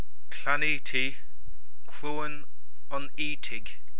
Pronunciation